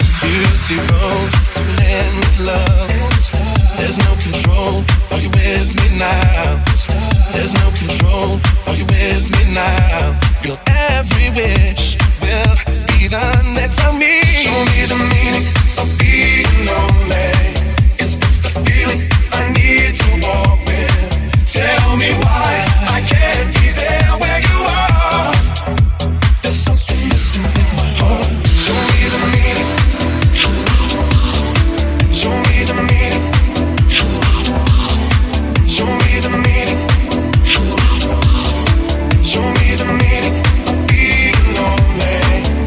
Genere: deep house, successi, remix